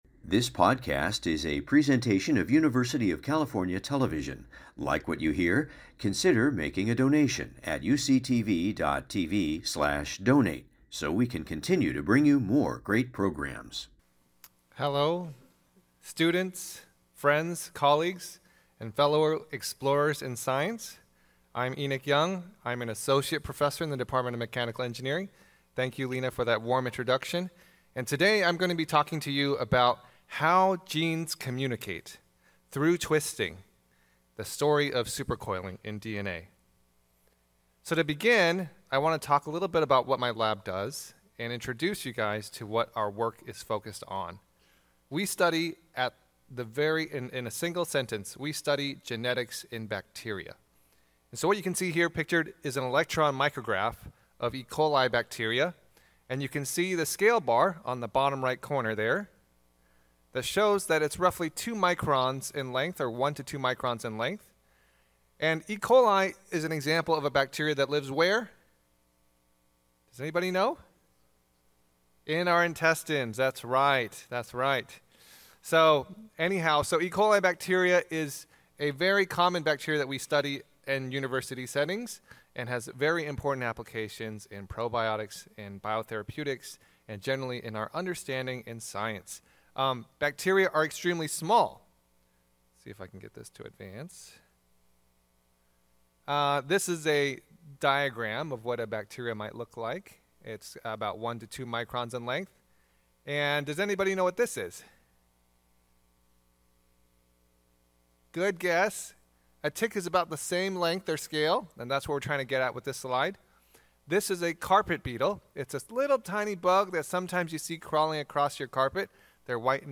1 Sustainable Boards: European and French Perspectives at the time of the EU Omnibus Package: 3CL Seminar 31:10